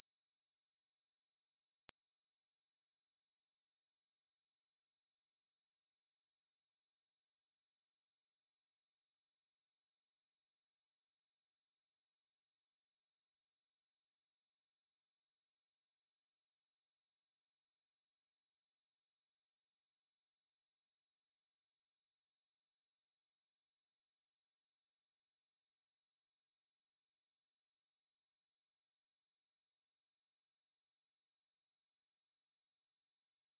1°) Le bruit se produit à l'intérieur ET l'extérieur de la maison.
Par contre, depuis ma déposition, la fréquence des "bruits" a augmenté et monte maintenant jusqu'aux alentours de 17,5 kHz.
4°) J'ai fait les mesures avec eau, électricité, gaz, VMC coupés et la nuit (quand le bruit est le plus fort), en utilisant 3 PC différents dans des pièces différentes (2 laptops et un desktop) avec des microphones différents (realtek, creative, samson C03U). l'échantillonnage de audacity est à 96000 Hz.